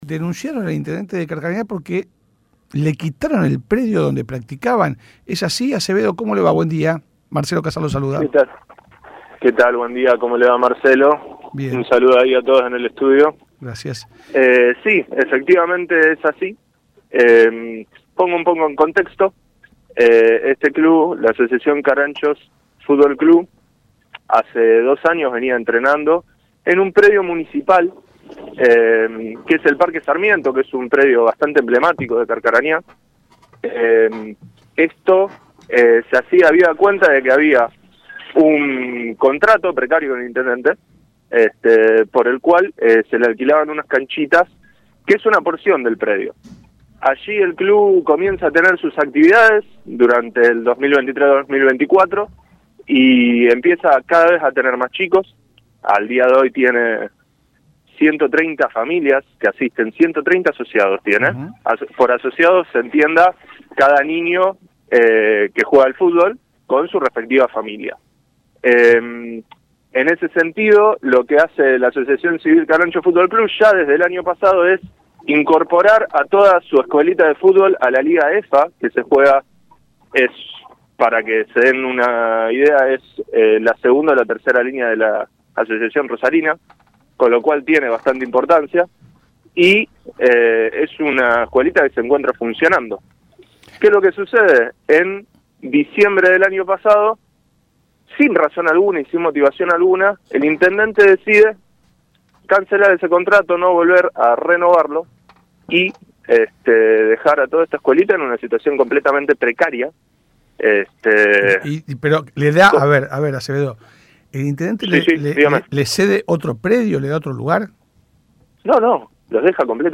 habló en el programa La Barra de Casal y se refirió a la situación que atraviesa la institución, que utilizaba una parte del predio municipal Parque Sarmiento para poder realizar sus actividades.